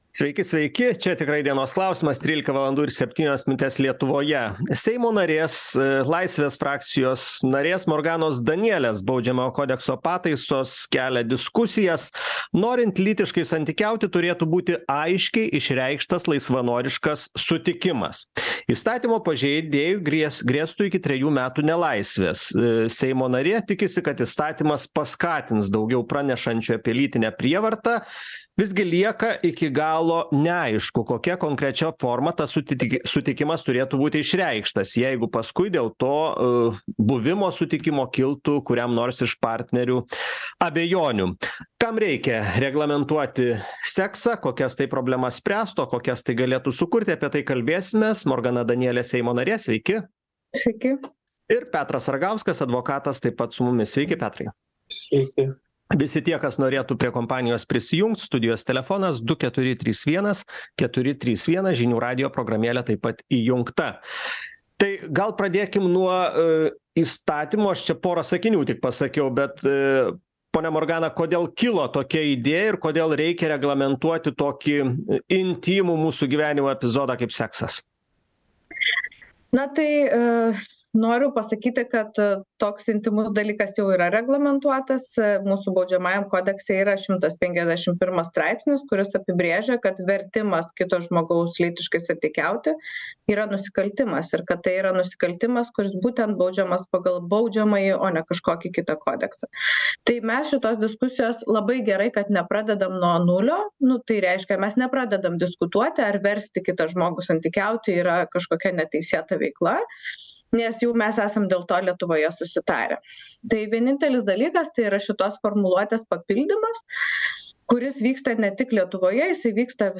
Diskutuoja: Seimo narė Morgana Danielė